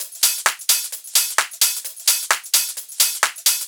Hat Shuffle 01.wav